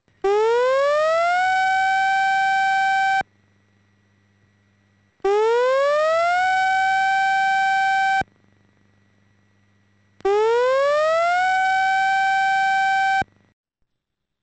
避難指示サイレン.mp3